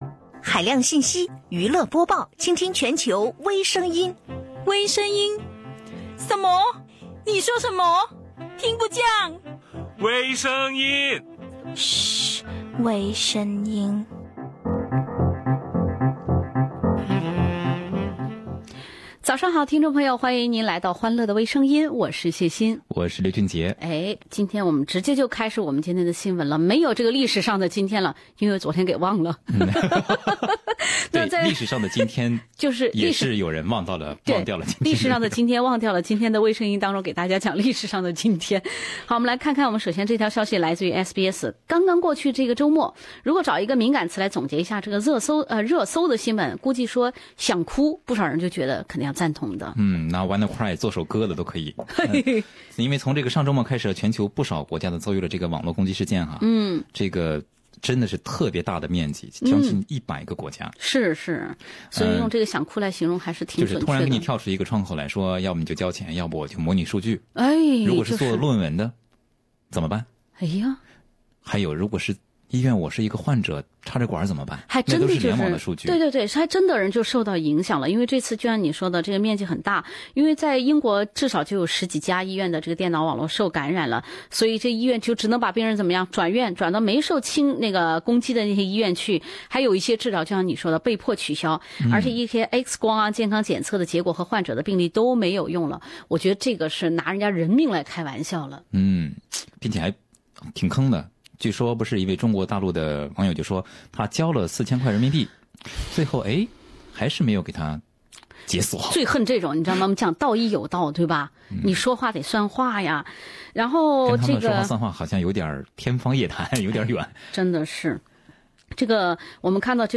女婿 铁汉柔情 设网恋陷阱骗丈母娘少女萌心的钱，全日空航空公司ANA为麸质过敏乘客提供的早餐仅是一根香蕉，让一些乘客WannaCry。另类轻松的播报方式，深入浅出的辛辣点评，包罗万象的最新资讯，倾听全球微声音。